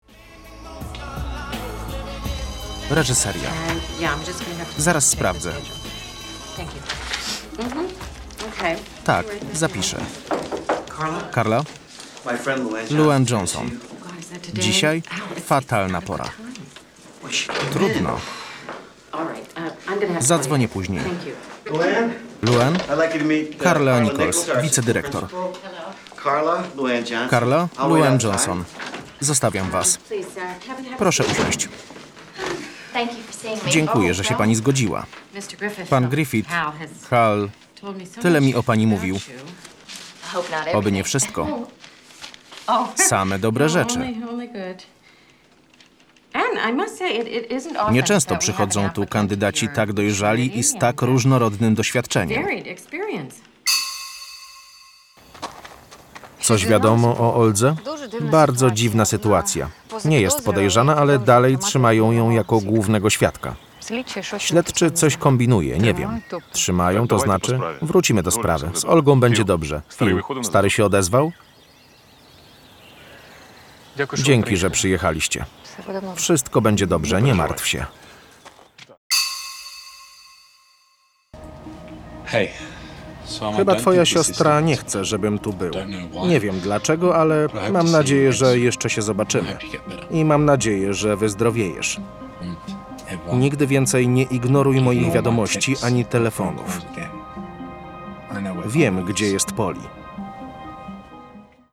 DEMO FILMOWE. Lektor – polska wersja językowa. (kompilacja)